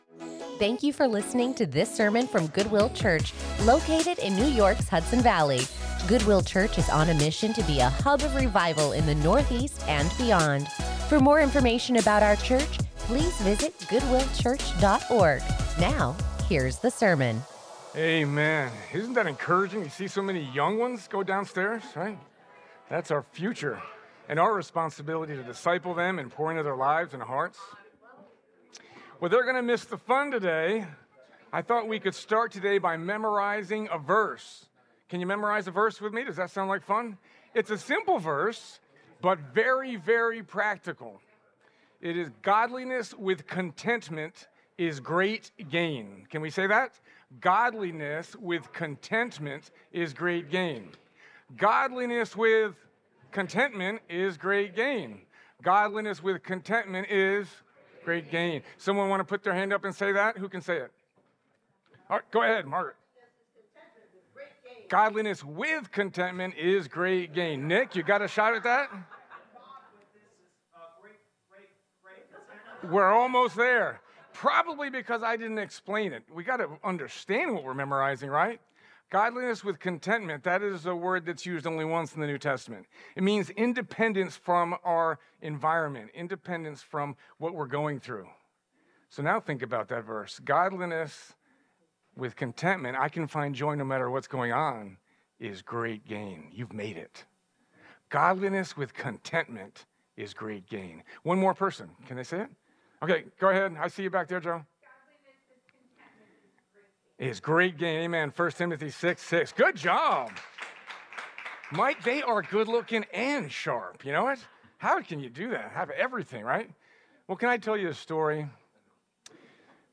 2 Eagles and a Vine | Beacon | Goodwill Church